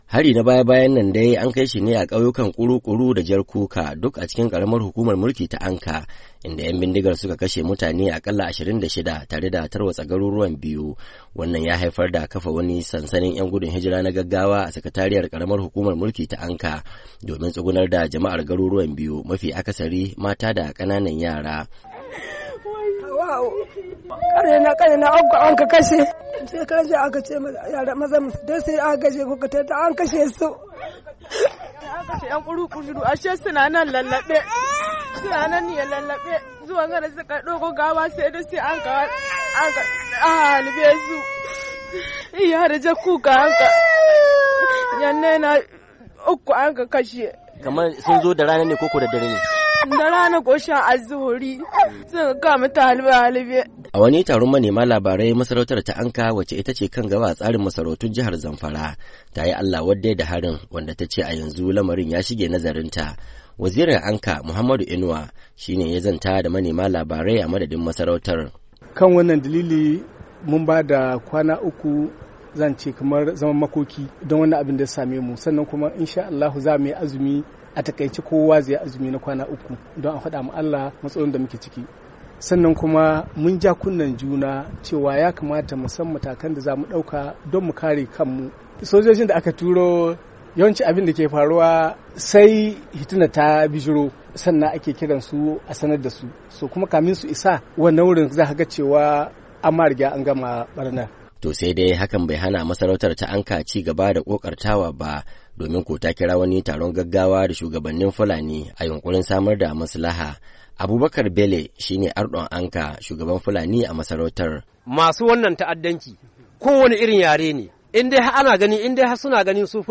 Wata da ke magana cikin kuka da yanayin ban tausayi, ta ce mazansu sun tafi dauko gawarwakin mutanen da aka kashe, ashe ‘yan bindigar sun yi kwantan-bauna, inda suka afka musu da harbi suka kashe su.